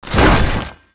带金属声的倒地zth070518.wav
通用动作/01人物/02普通动作类/带金属声的倒地zth070518.wav
• 声道 單聲道 (1ch)